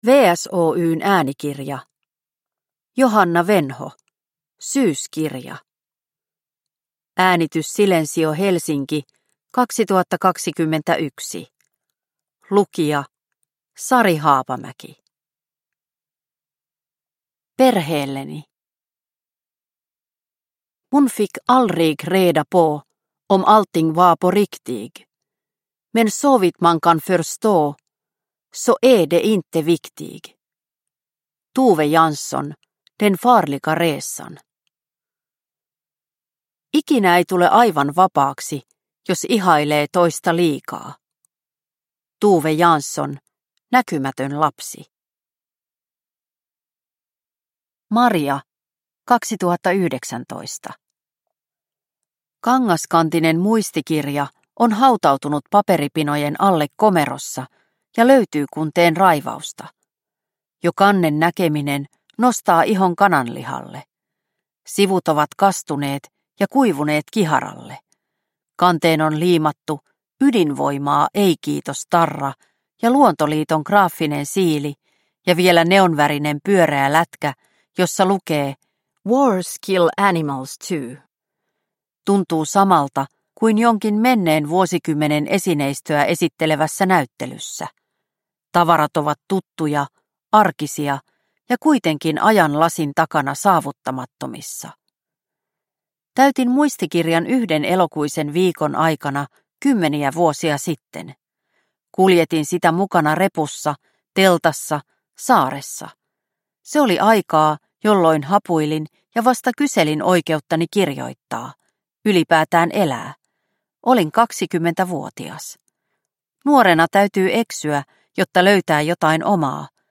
Syyskirja – Ljudbok – Laddas ner